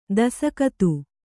♪ dasakatu